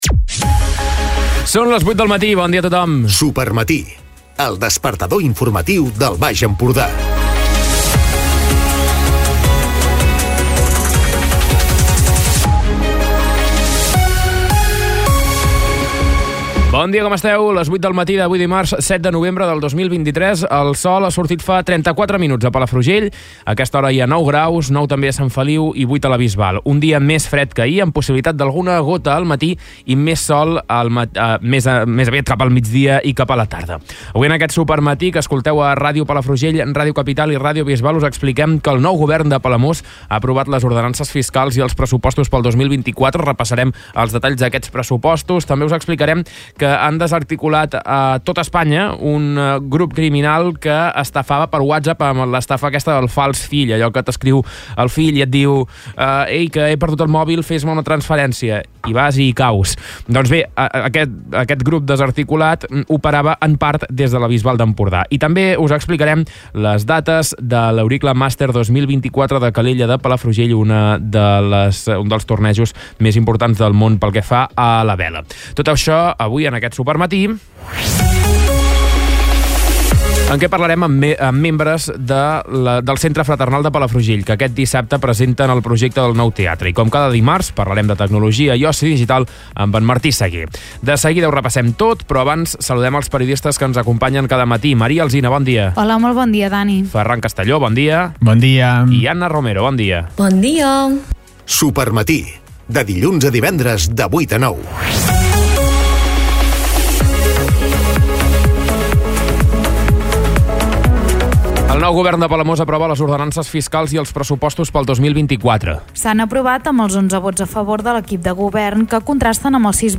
Escolta l'informatiu d'aquest dimarts